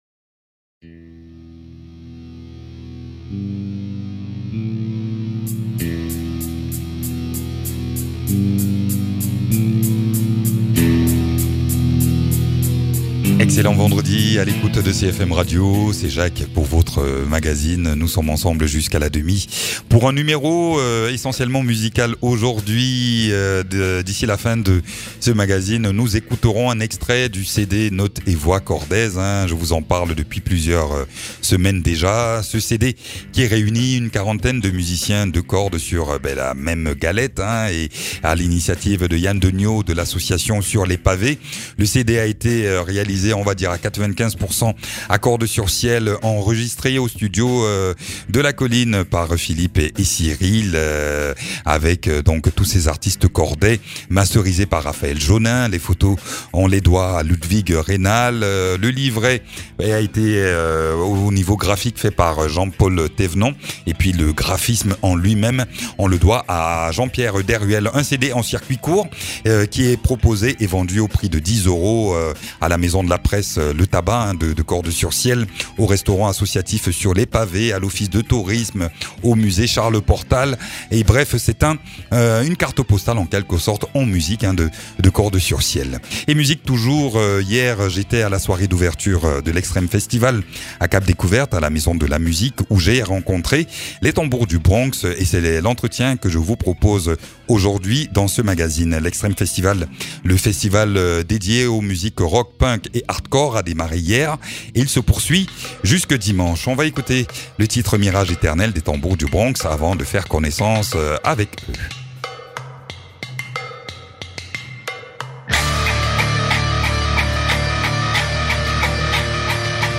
Chouette soirée d’ouverture de l’Xtreme Festival à Cap Découverte hier soir où les Tambours du Bronx se sont livrés à notre micro.